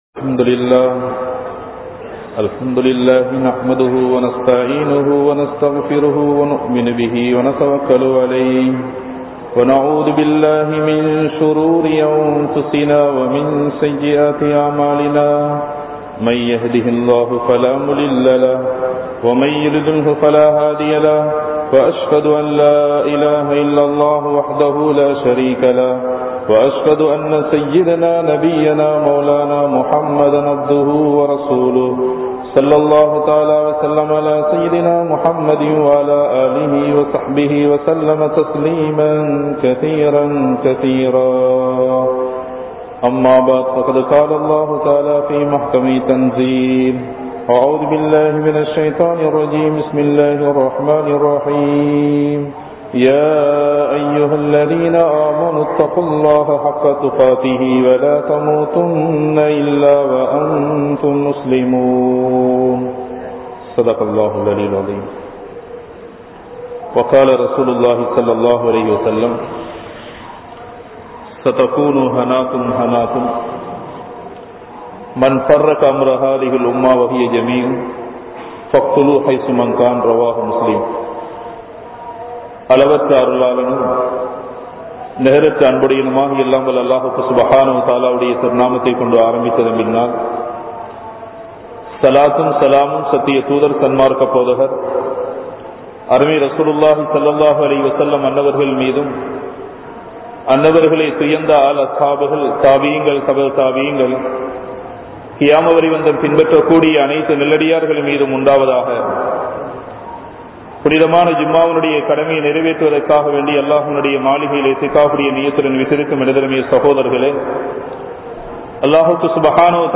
How to Face Current Challenges | Audio Bayans | All Ceylon Muslim Youth Community | Addalaichenai
Aluthgama, Dharga Town, Meera Masjith(Therupalli)